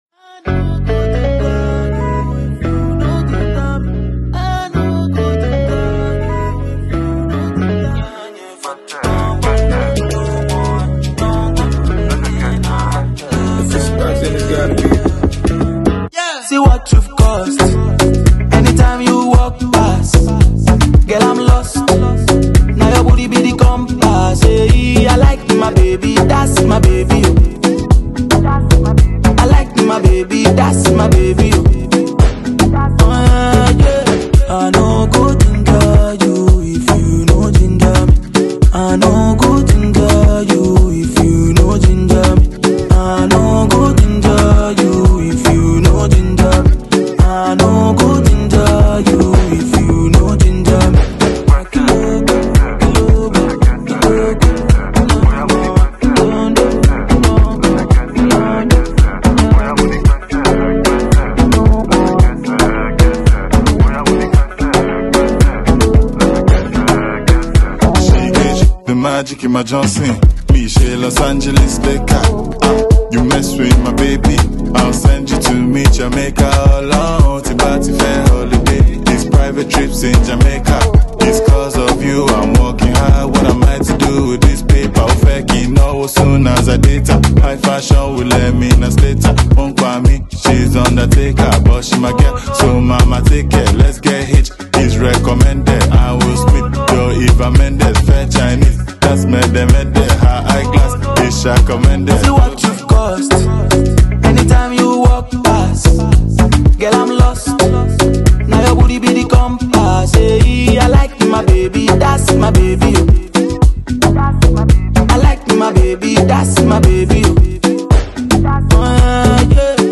Nigerian alte pioneer